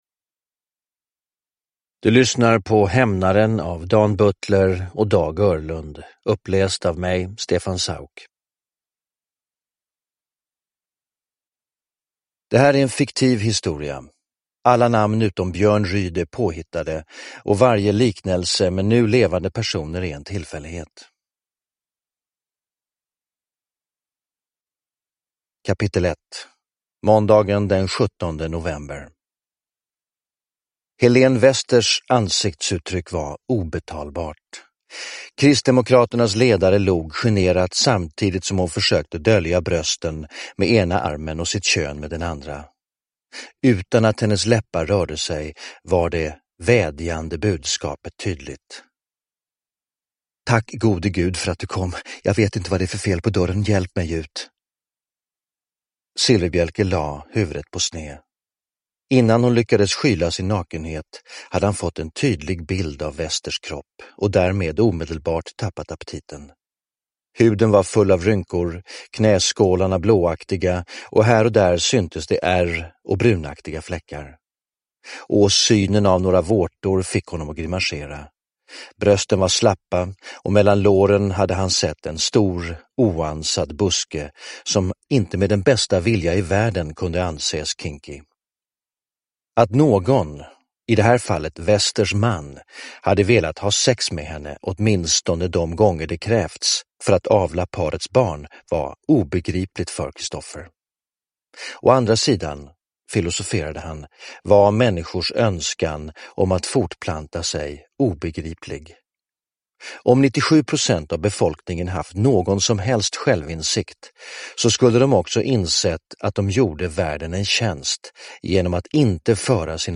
Uppläsare: Stefan Sauk
Ljudbok